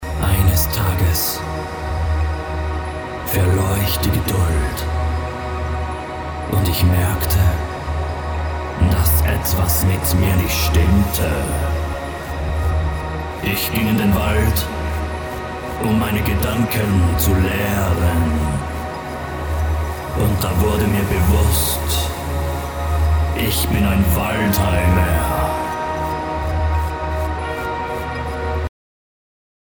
Monster-Stimme :eek:
Gemacht folgendermaßen: 1. Stimme verstellt, so halb gegrowled 2. Kopie gemacht und 8 (oder so) halbtöne runtergepitched 3.
Auf die gepitchte Version auch so ein Preset aus dem Ambient pack 5. Beiden über ne FX mit einem weiteren Presets aus dem Ambient pack (Backward FX)